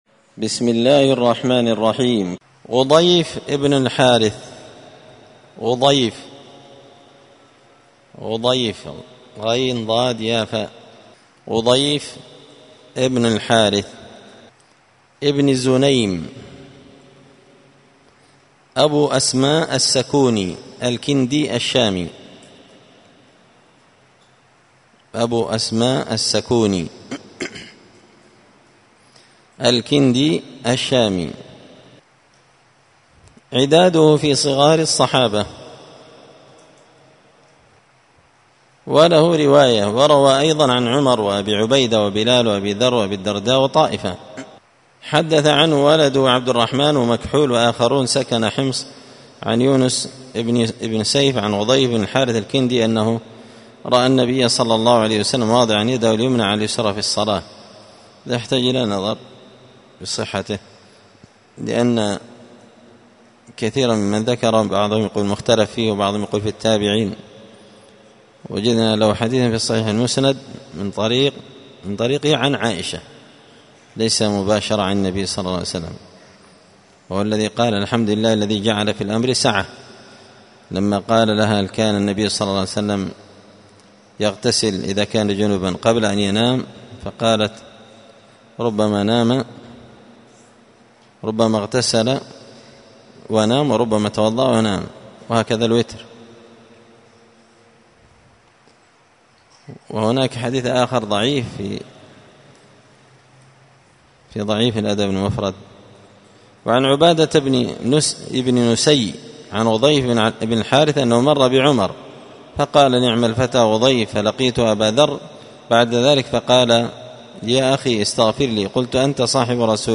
قراءة تراجم من تهذيب سير أعلام النبلاء
مسجد الفرقان قشن المهرة اليمن